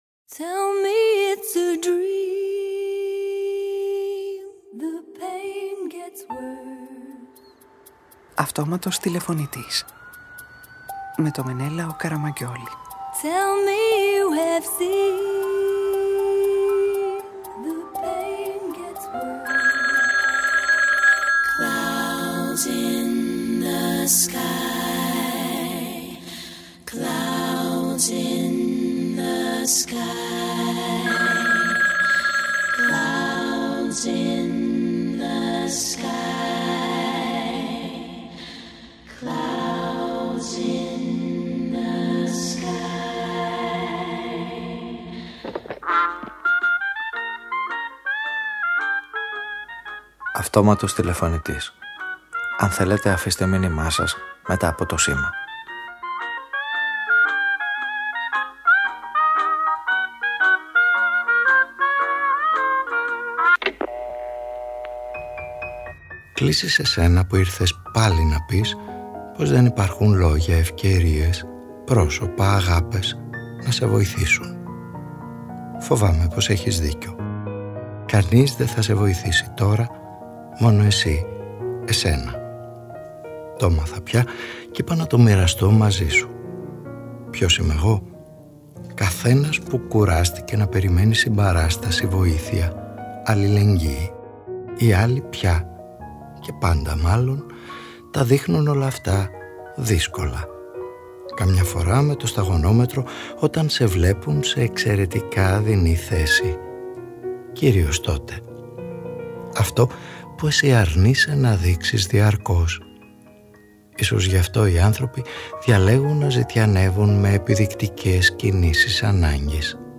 Ο ήρωας της σημερινής ραδιοφωνικής ταινίας δε βρίσκει συνδρομή πουθενά και καταφεύγει στην μόνη -ίσως- πηγή ουσιαστικής βοήθειας: είναι οι άλλοι; ο εαυτός του; οι αγάπες του; ή όσα φοβάται;